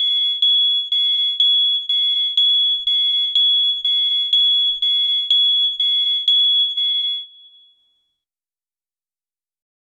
high danger alert unique sharp beep sound effect, one shot
high-danger-alert-unique--2sl5txvb.wav